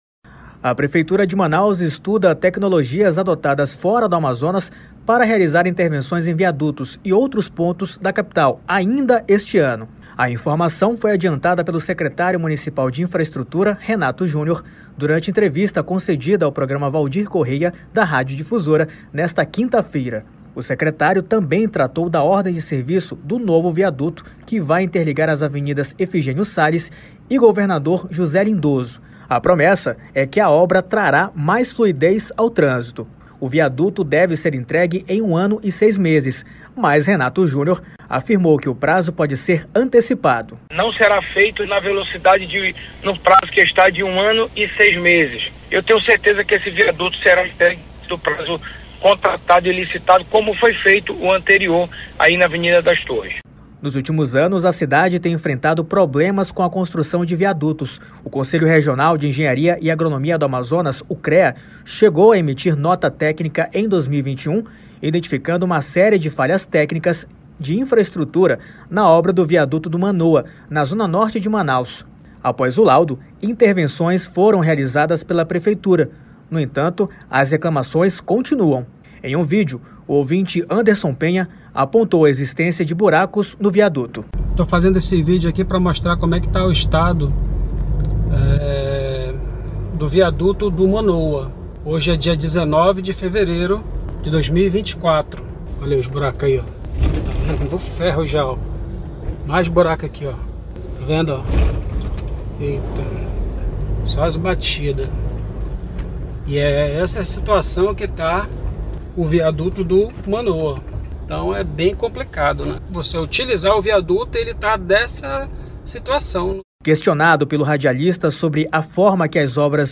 Questionado pelo radialista sobre a forma que as obras de viadutos são executadas e planejadas nos últimos anos, o secretário culpou gestões anteriores e disse que a administração herdou problemas mal resolvidos:(Ouça)
Motoristas de aplicativo também manifestaram preocupação com a qualidade das obras:(Ouça)